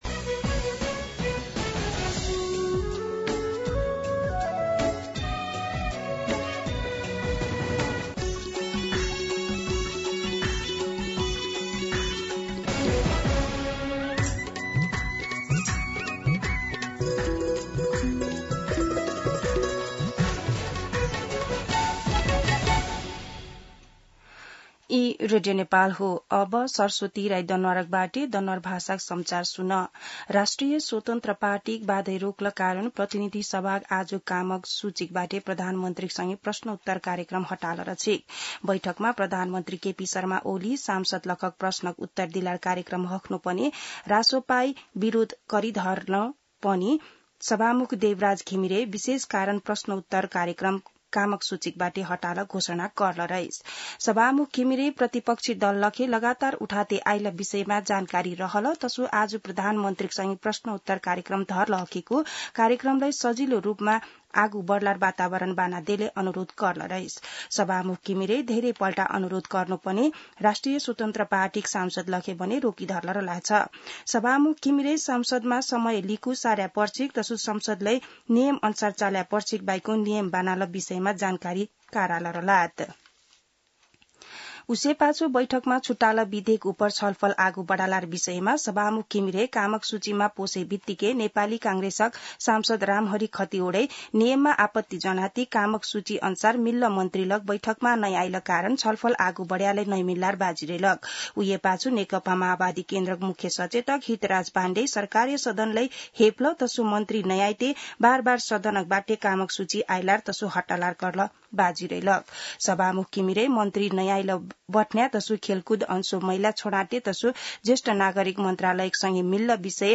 दनुवार भाषामा समाचार : ६ असार , २०८२